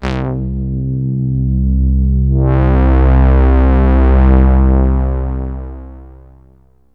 STRINGS 0011.wav